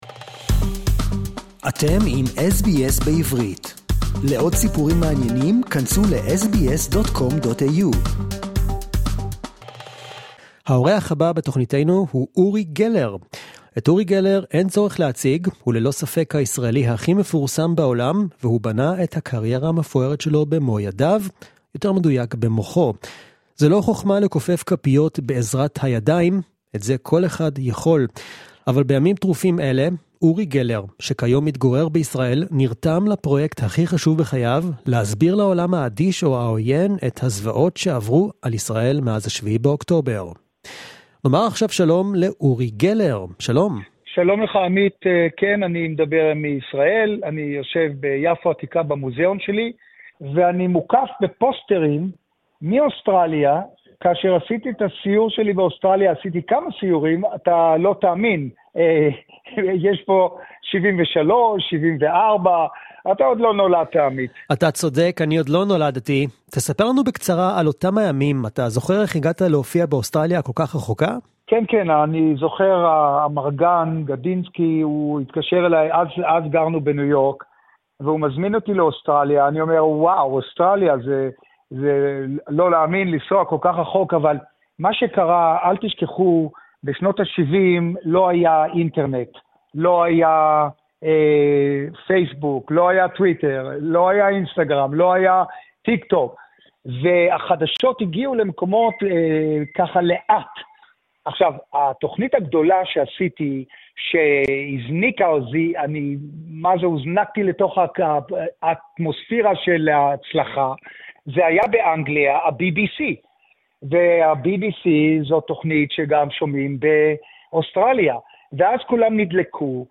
Uri Geller is probably one of the most famous Israeli magicians around the world. In this exclusive interview to SBS Hebrew, Uri talks about his successful career and the aftermath of the events of 7th of October. (Hebrew Interview)